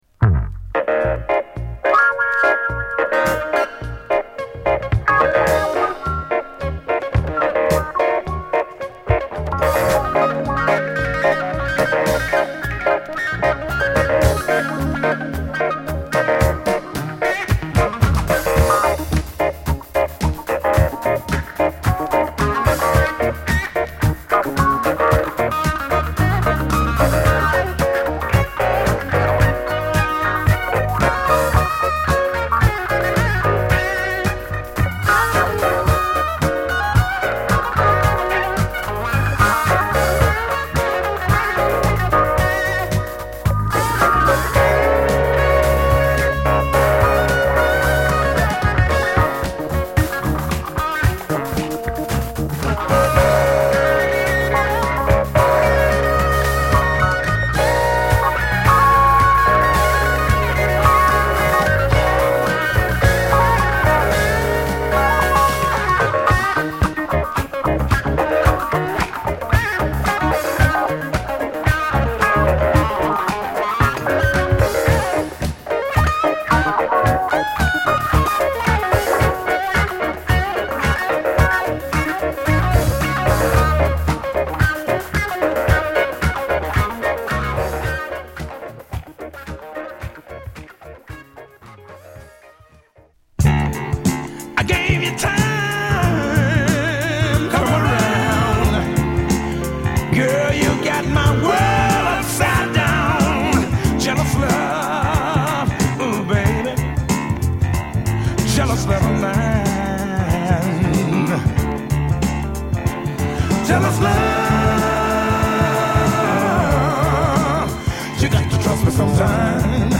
苦みばしったグルーヴィーなソウルを本作でも披露。